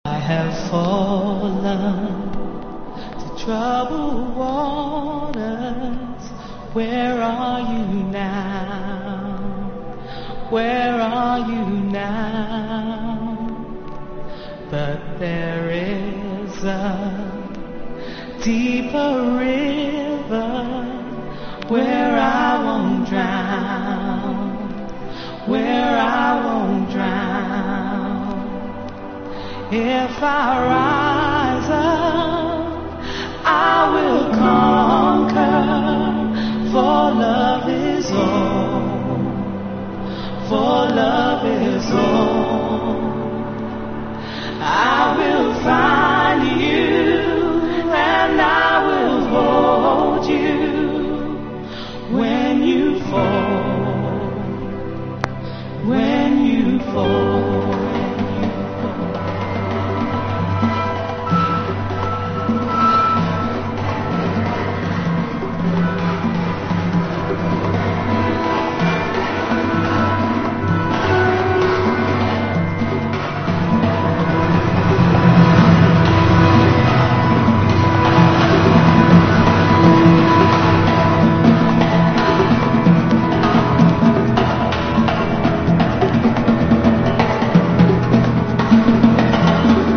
VOCAL VERSION